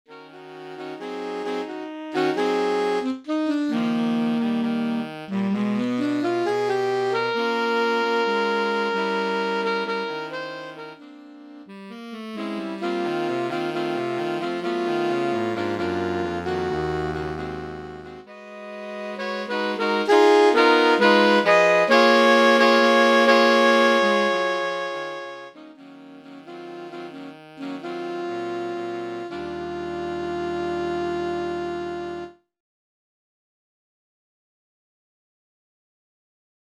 Saxophone Quartet Edition